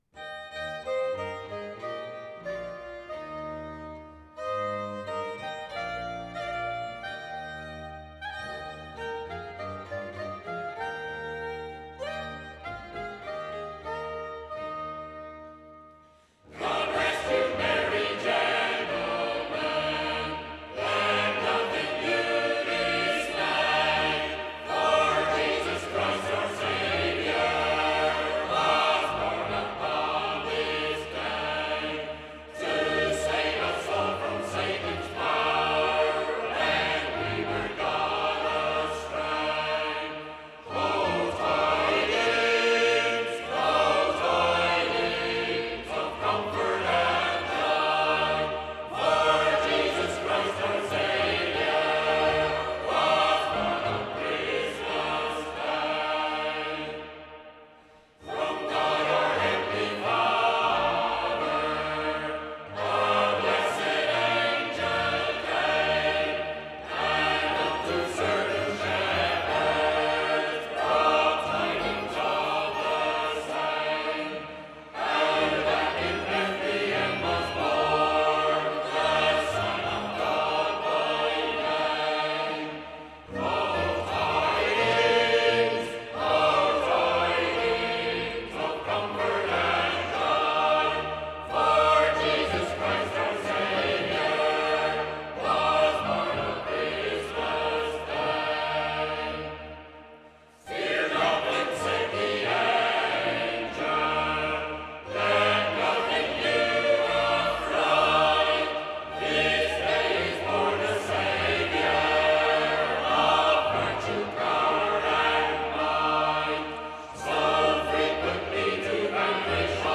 Canto
Música religiosa